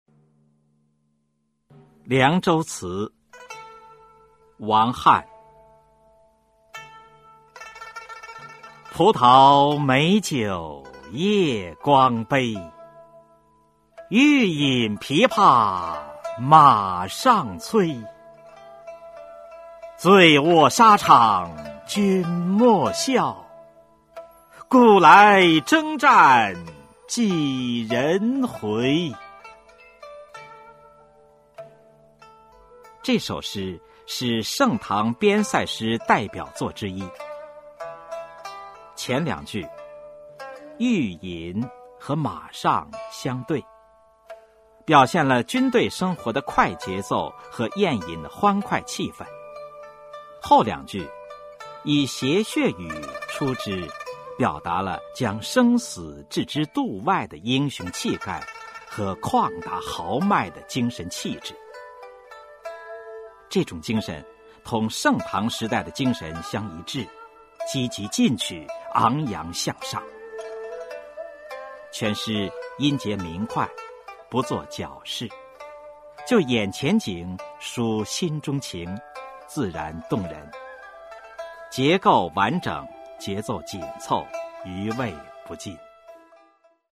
语文教材文言诗文翻译与朗诵 初中语文九年级下册 目录